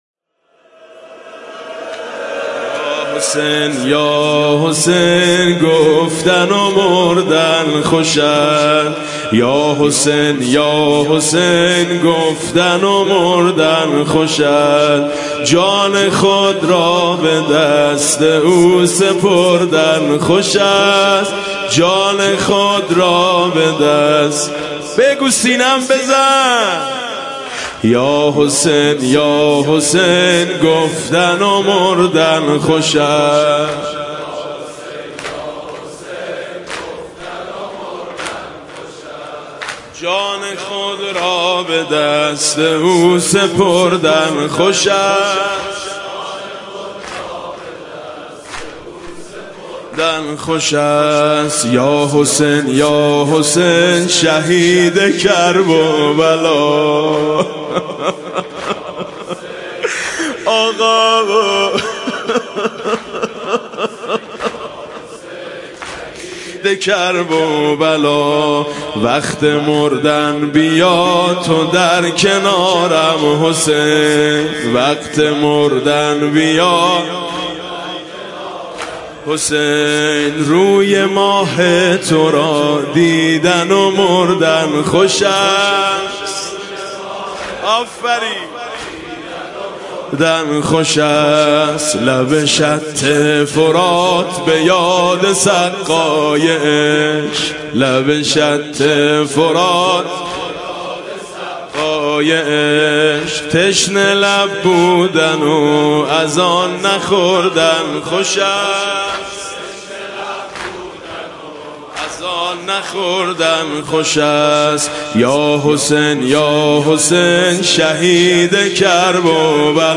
نوحه جديد
مداحی صوتی